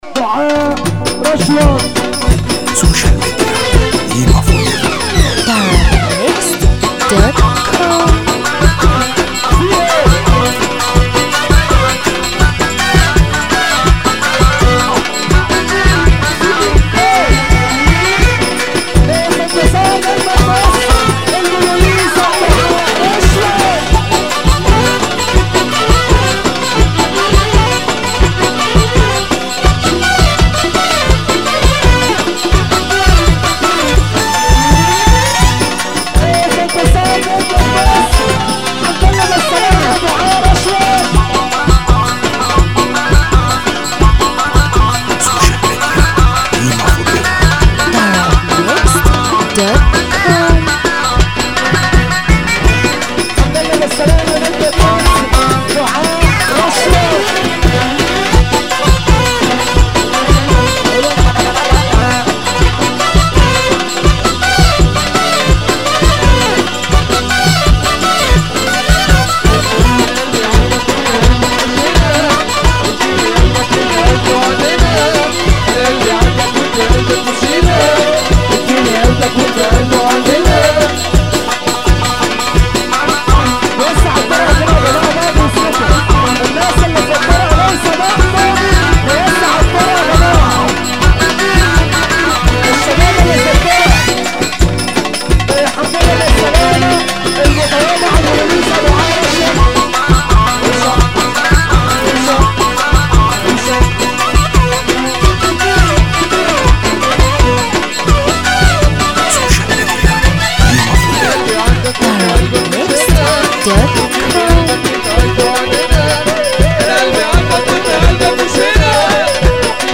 موال
حزينة